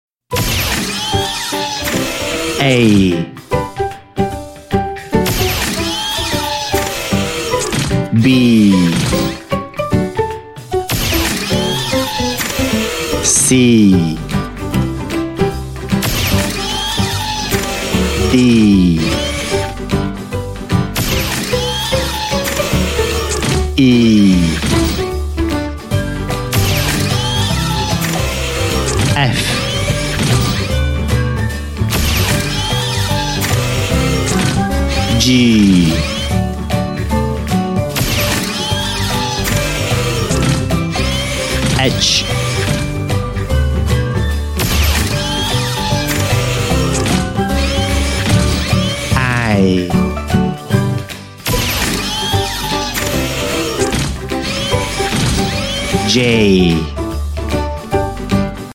Robot Alphabet (A J) sound effects free download